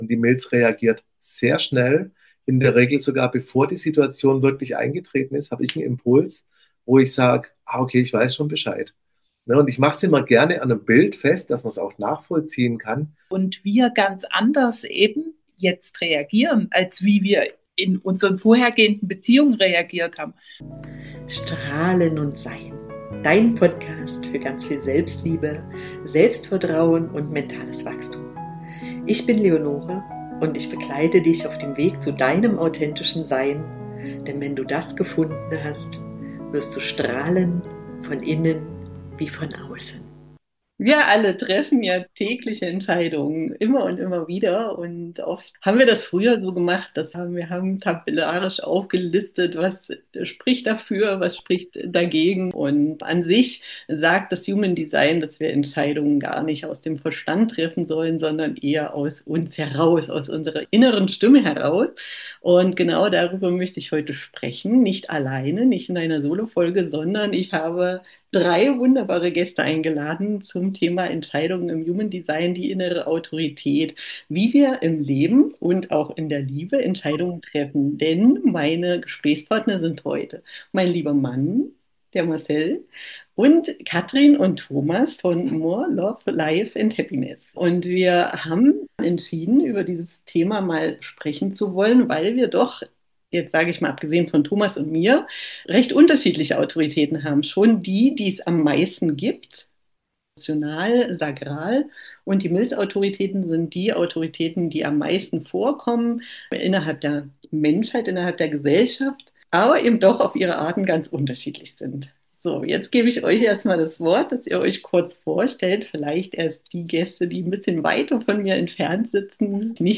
Sie ist wie ein ehrliches, inspirierendes Freundschaftsgespräch über das, was es heißt, sich selbst wiederzufinden.